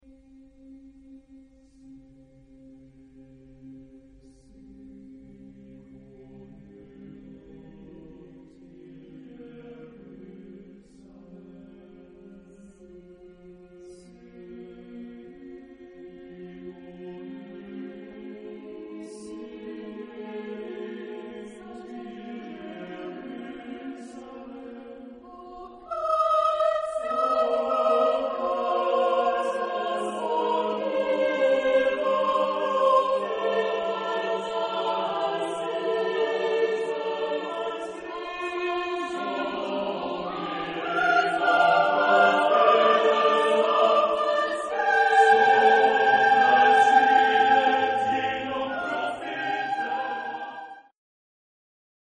SATB (4 voices mixed).